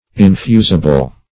Infusible \In*fu"si*ble\, a. [From Infuse, v.]